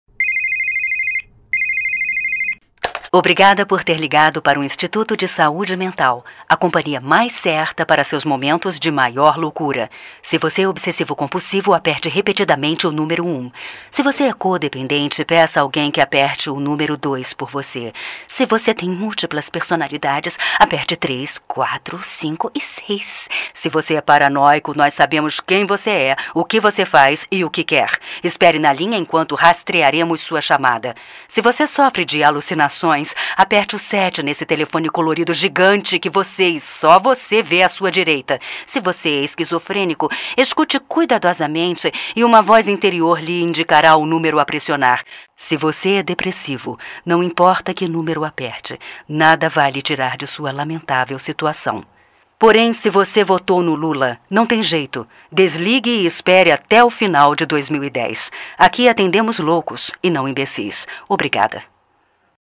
Secretária Eletrônica Do Hospício
secretaria-eletronica-do-hospicio.mp3